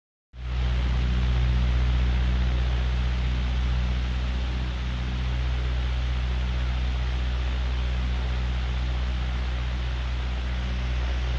Звук пропеллеров дирижабля вдали